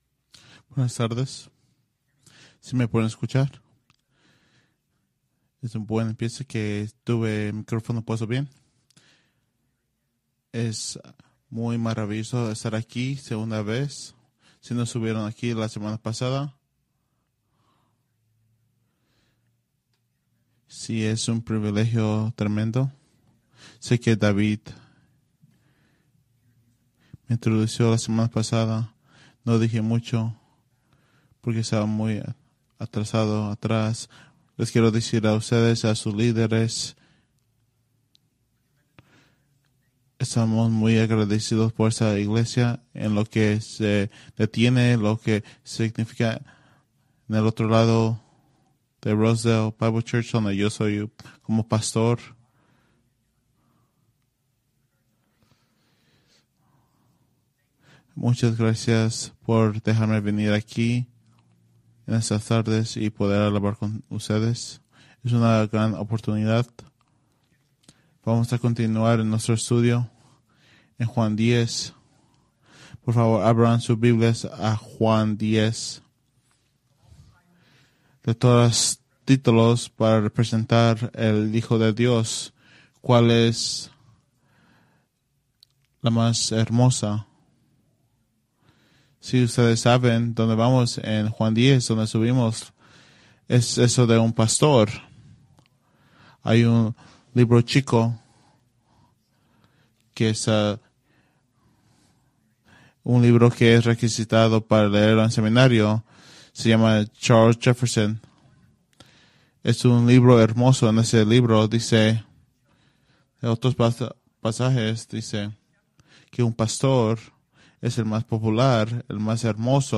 Preached August 4, 2024 from John 10:11-21